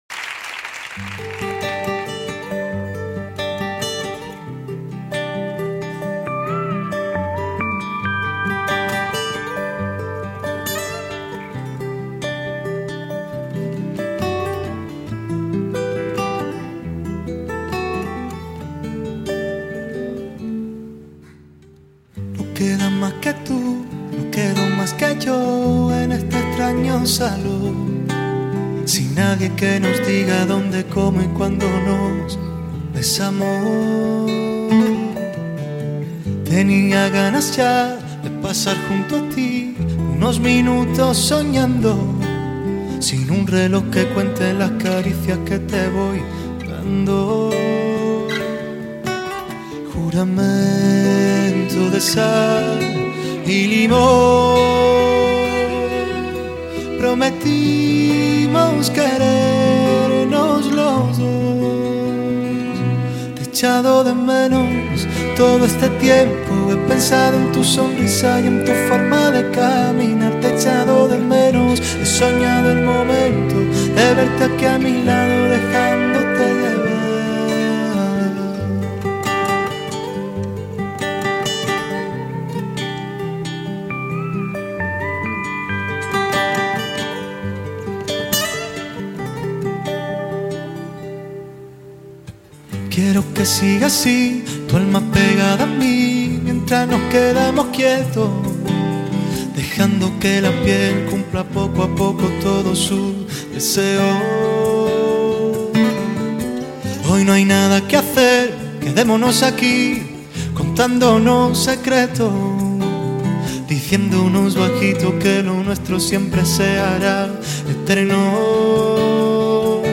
听到这首清新的歌曲，看到这首歌曲的创意MV，你的心里是否也存在着这样一个人，让你思念，让你希望把自己变成礼物